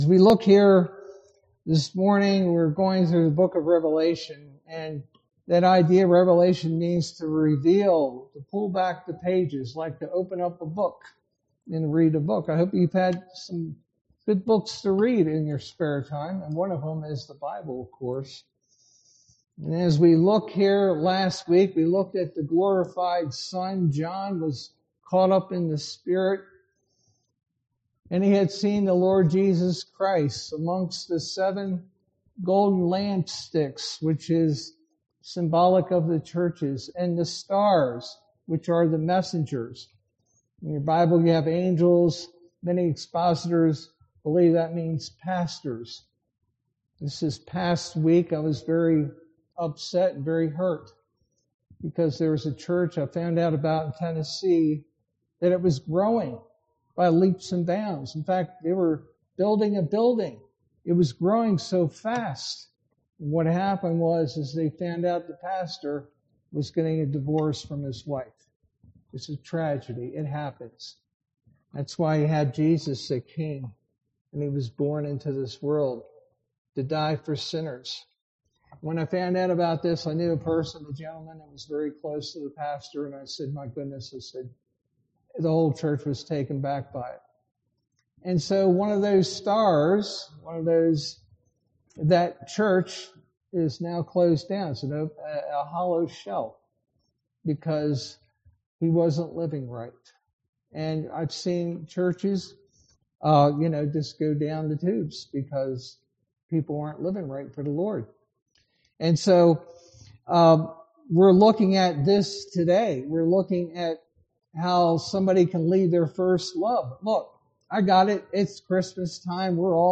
Sermon verses: Revelation 2:1-7